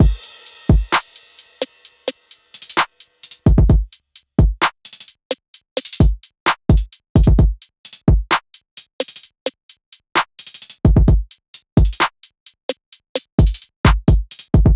SOUTHSIDE_beat_loop_cheddar_full_01_130.wav